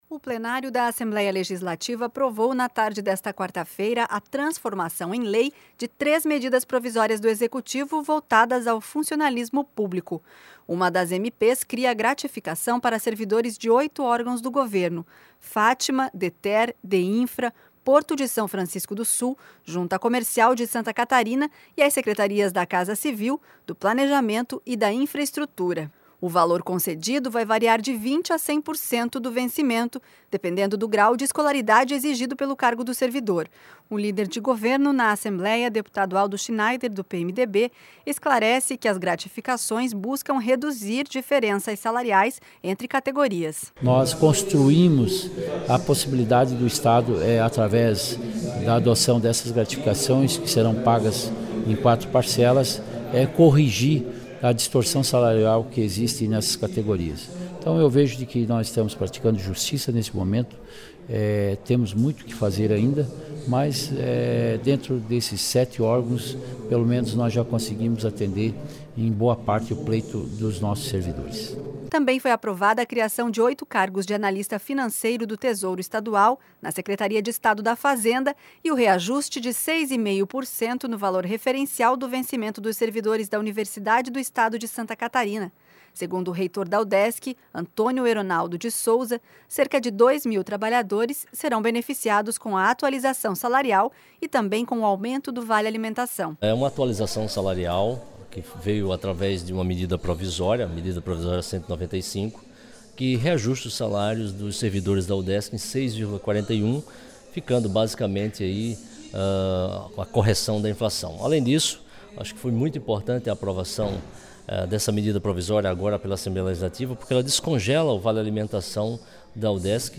Entrevistas: deputado Aldo Scheneider (PMDB), líder de governo na Assembleia Legislativa e Antônio Hernaldo de Sousa, reitor da Universidade Federal de Santa Catarina